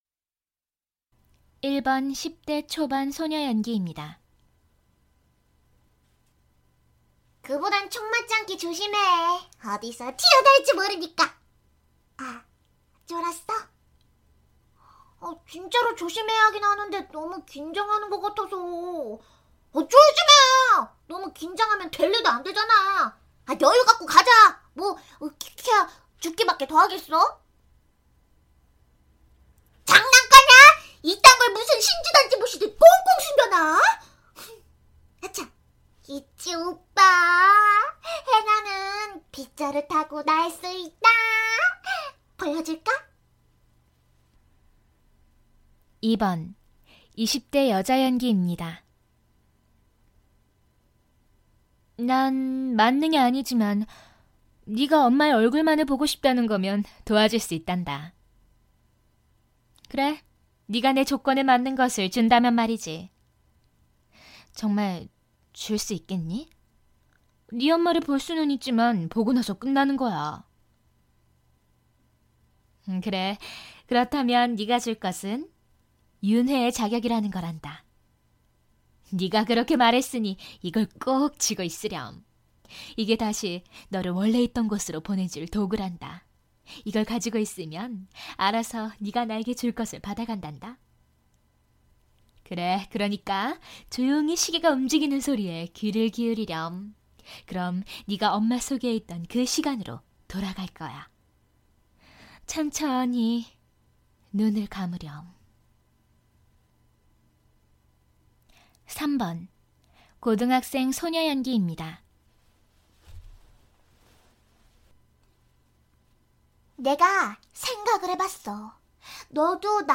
성우샘플